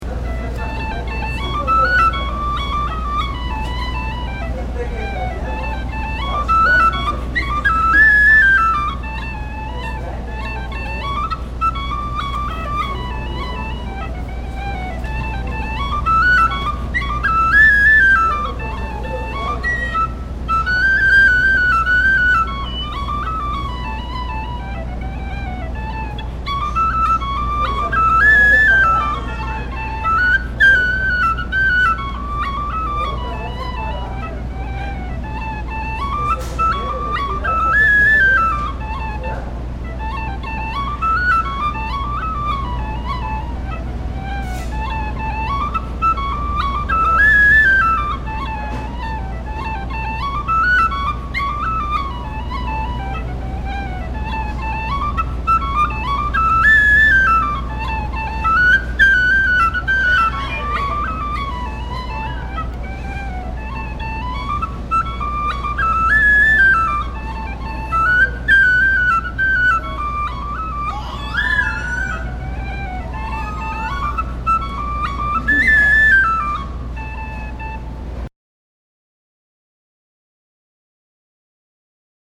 These are the tunes that we learned (or were supposed to learn) in 2007 for the Peninsula session.